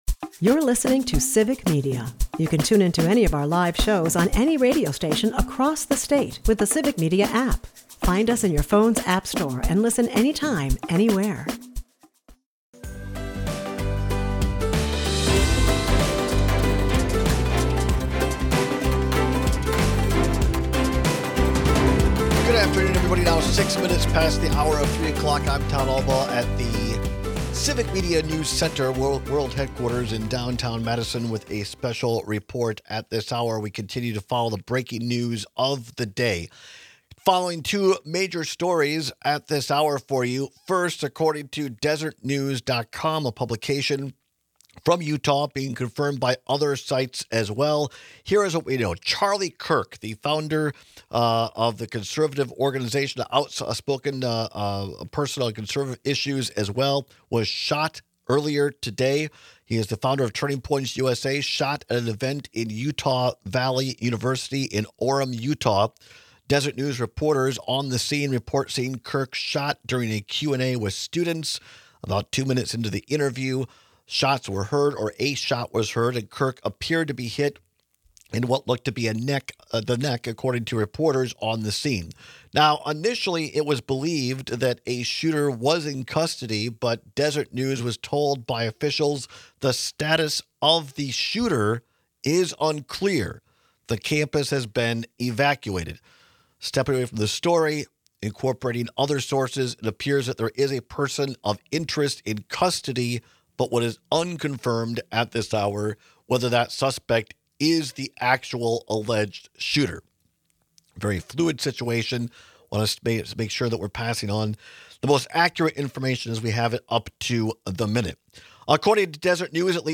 At the bottom of the hour, we welcome Milwaukee County Executive David Crowley to speak on his newly announced run for Governor of Wisconsin. He shares his journey into public service and what he would focus on as Governor.